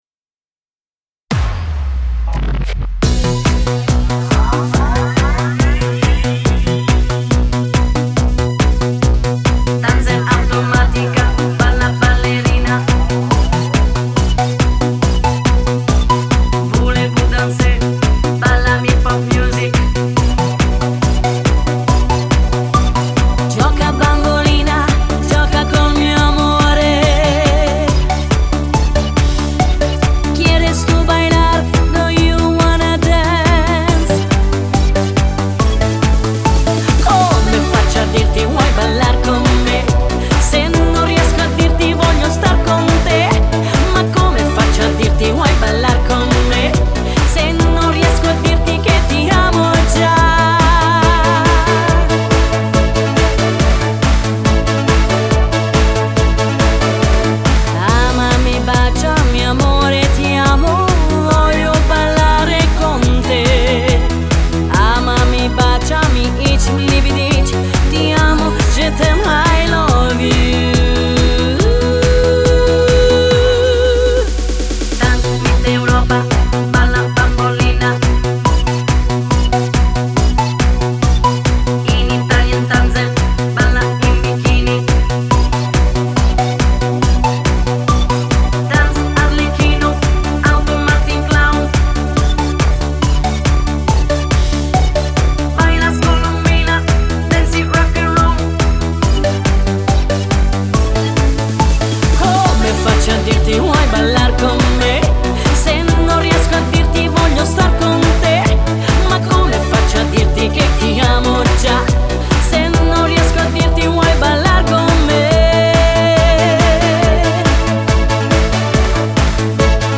Ballo di gruppo